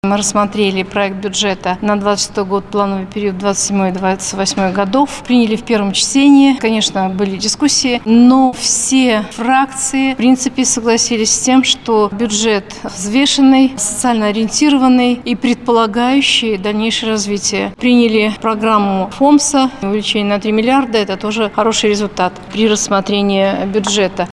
Прогноз доходов на 2026 год составляет более 504 млрд рублей, расходы запланированы в сумме свыше 536 млрд рублей, — сообщила председатель регионального Заксобрания Людмила Бабушкина.